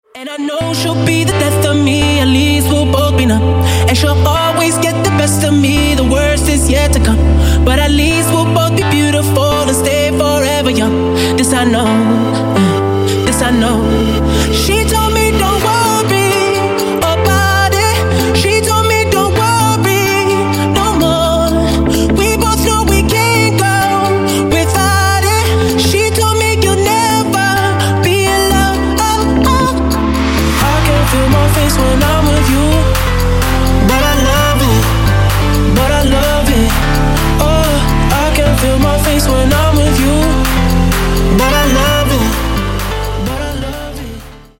• Качество: 160, Stereo
поп
мужской вокал
dance